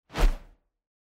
n1_ui_sound_refresh_click.mp3